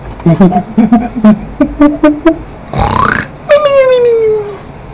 SLAKING.mp3